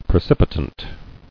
[pre·cip·i·tant]